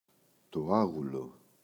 άγουλο, το [Ꞌaγulo]